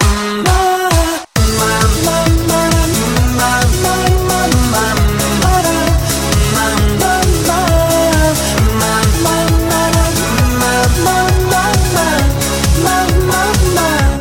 реалтоны pop